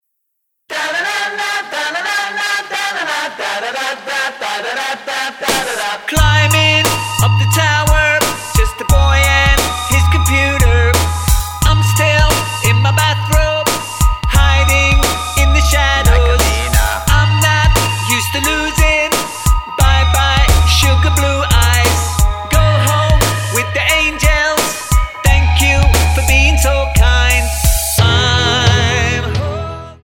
Tonart:C#m Multifile (kein Sofortdownload.